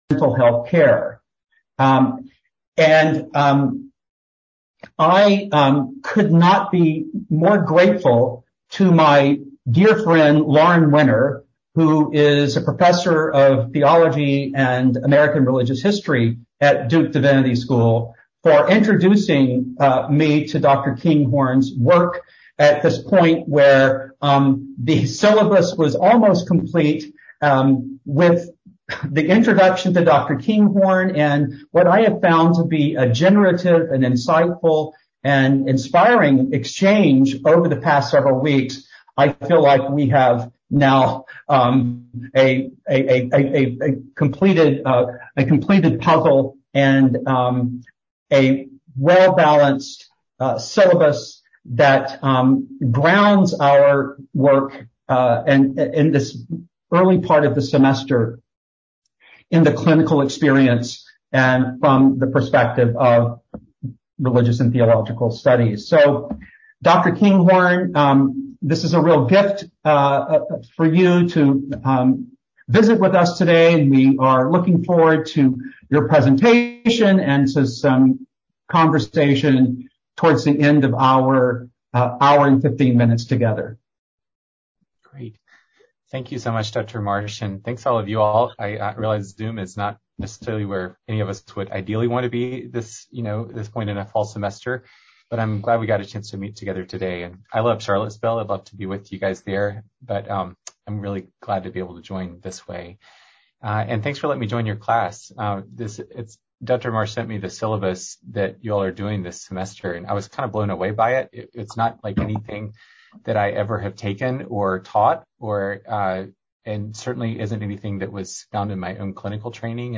In his presentation